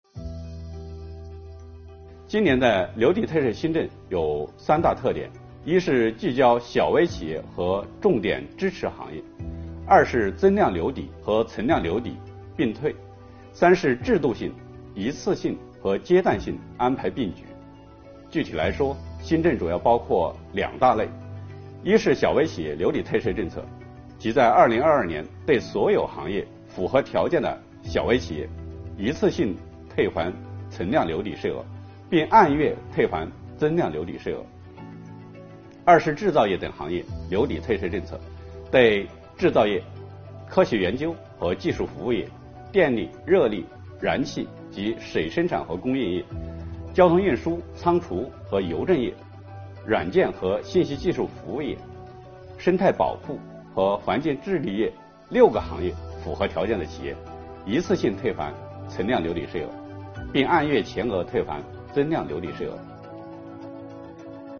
国家税务总局货物和劳务税司副司长刘运毛担任主讲人，详细解读了有关2022年大规模留抵退税政策的重点内容以及纳税人关心的热点问题。